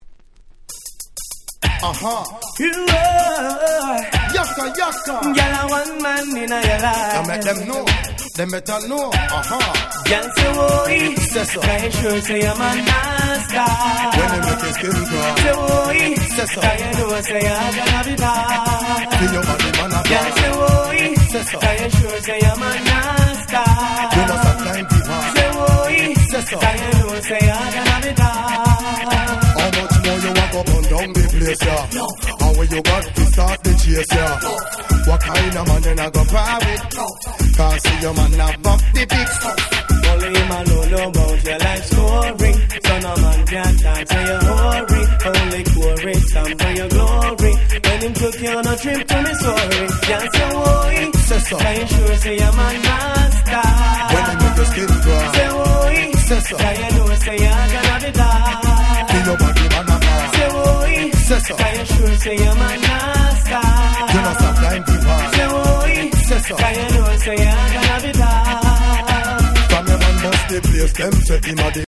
Dancehall Reggae Classics !!